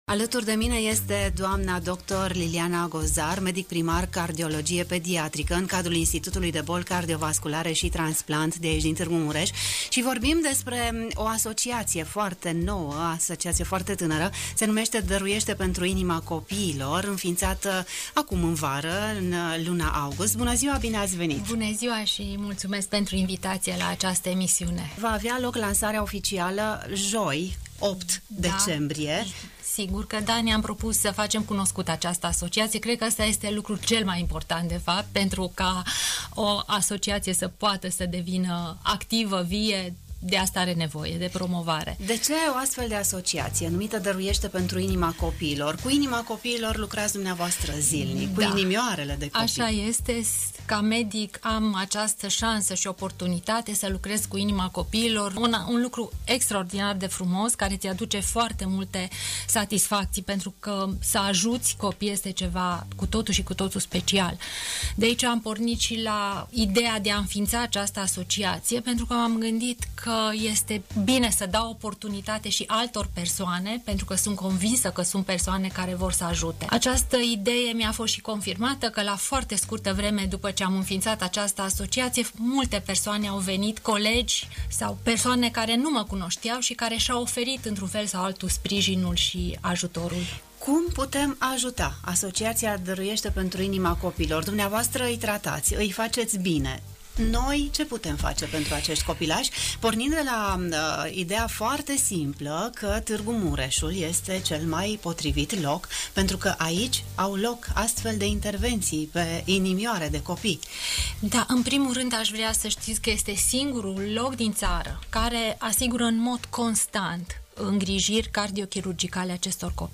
în emisiunea Pulsul zilei